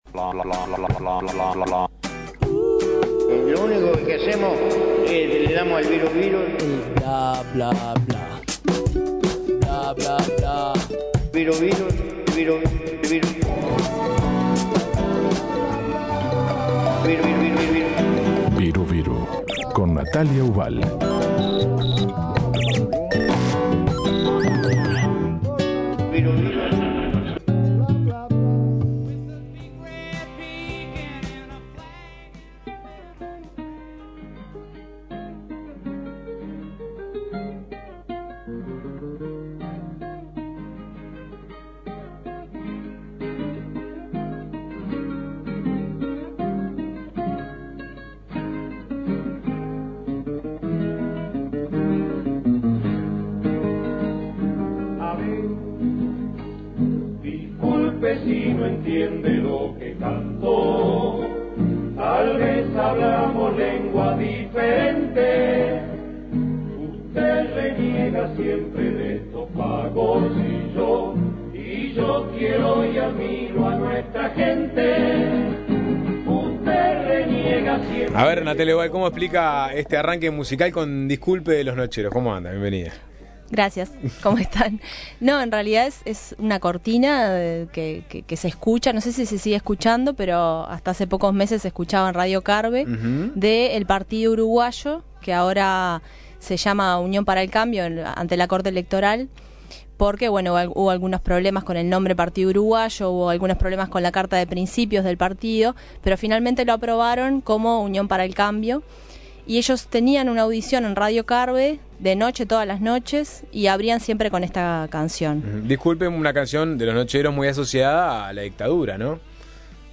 Además, trajo fragmentos de la audición radial de Unión para el cambio, más conocido como Partido Uruguayo.